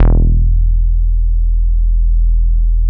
BASS 6.wav